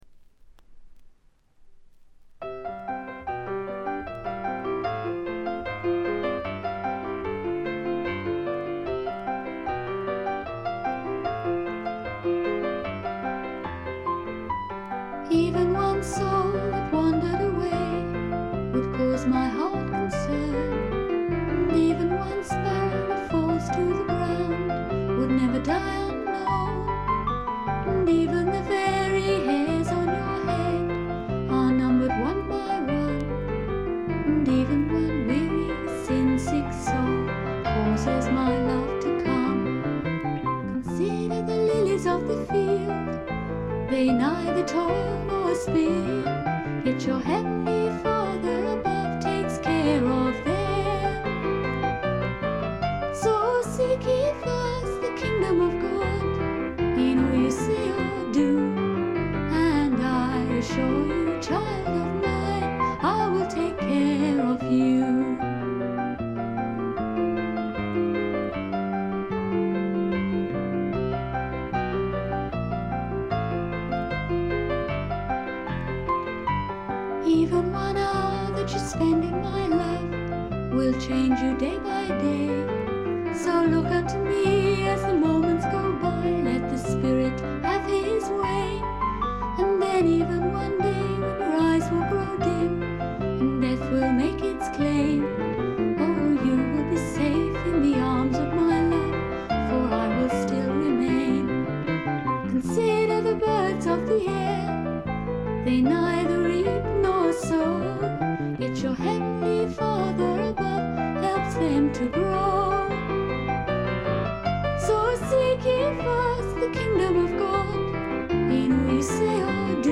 部分試聴ですが静音部での軽微なチリプチ程度。
古くからクリスチャン・ミュージック系英国フィメールフォークの名盤として有名な作品ですね。
試聴曲は現品からの取り込み音源です。